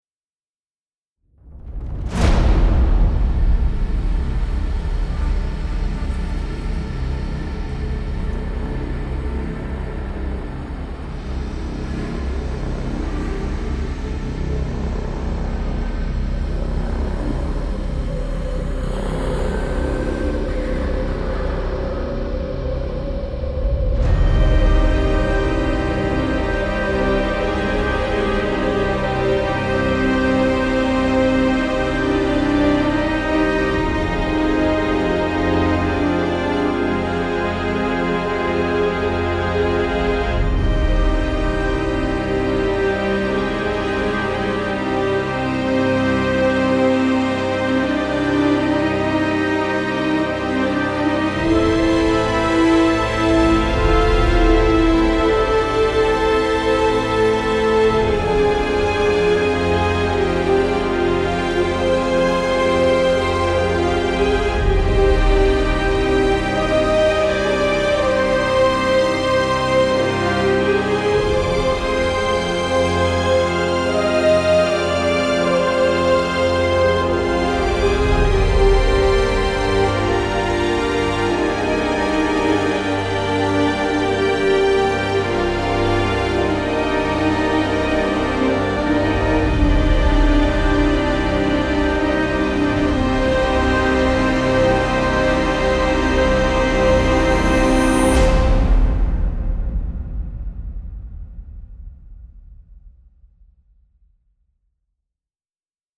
Bonne ambiance au début, avec les pads évolutifs.
L'entrée des cordes arrive trop tôt. Un peu trop lyrique et pleurnichard.